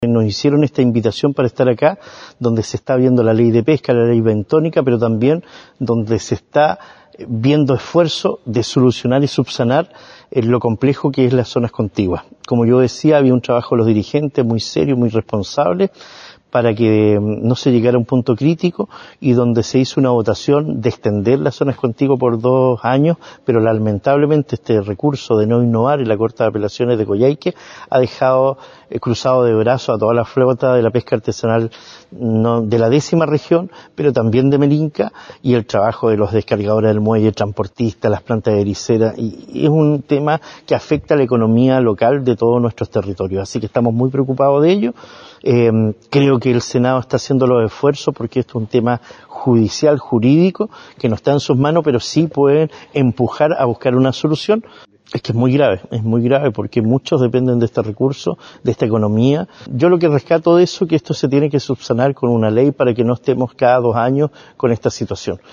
Esto ha conllevado que se inicien una serie de gestiones al más alto nivel, por ello el alcalde de Quellón Cristian Ojeda llegó al Senado para plantear este problema, ocasión en la que participó en la comisión de pesca de la cámara alta y expuso los serios inconvenientes que resultan de esta disposición judicial, propiciada por algunos sectores de la pesca artesanal de Aysén.